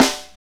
Index of /90_sSampleCDs/Northstar - Drumscapes Roland/SNR_Snares 1/SNR_H_H Snares x